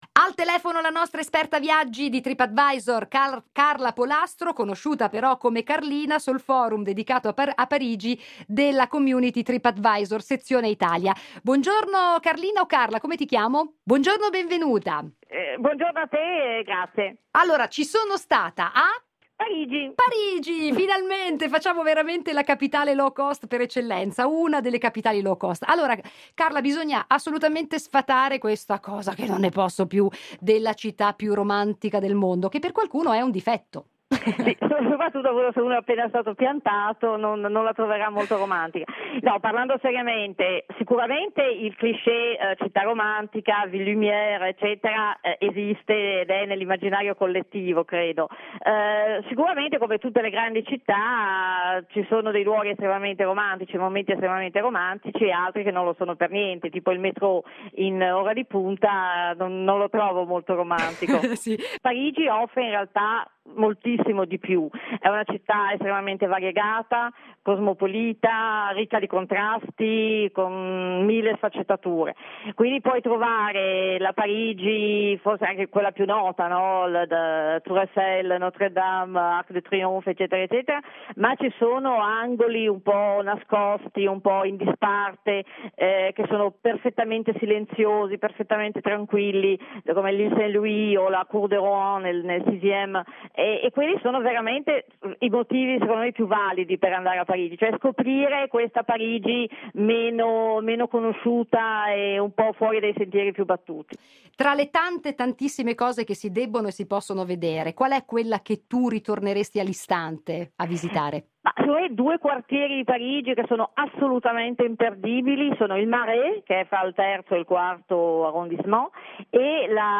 Intervista su Radio Capital